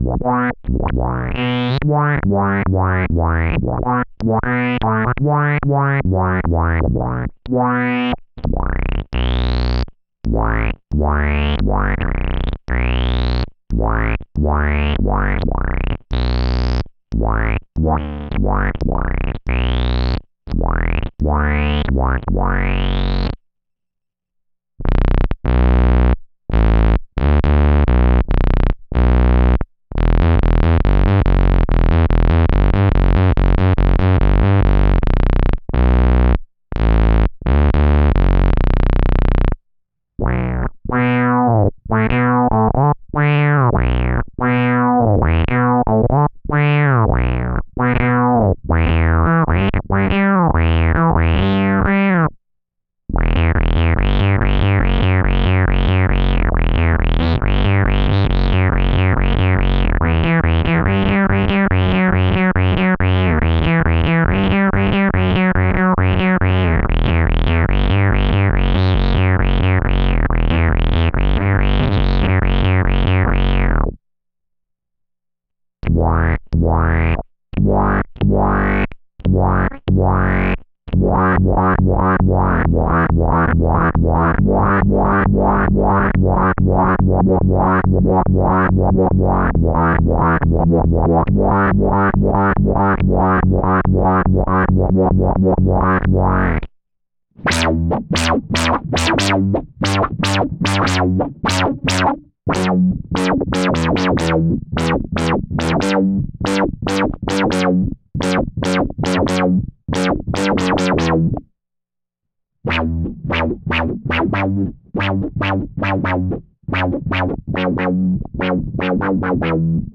I cycle through the presets I've created after about 90 minutes of experimenting with the pedal. Some of the patches sound great, others like crap. I also cycle through just the KORG and then the KORG with the OC-2 in front.
Korg_G5_noodling.mp3